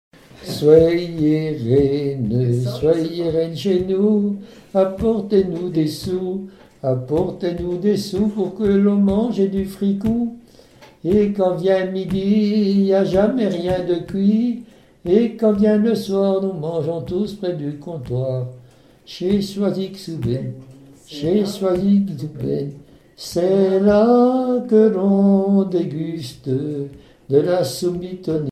Satires de personnages publics
Témoignages et chansons
Pièce musicale inédite